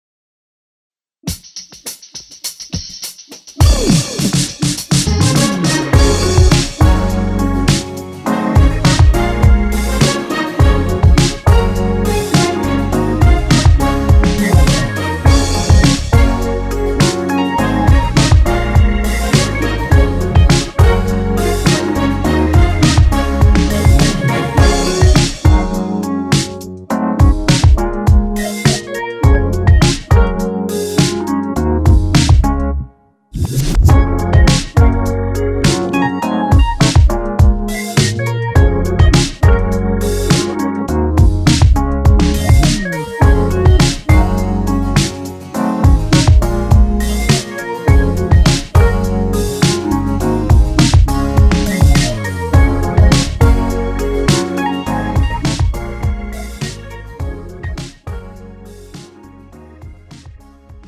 음정 (-1키)
장르 가요 구분 Premium MR